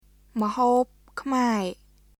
[ムホープ・クマエ　mhoːp kʰmae]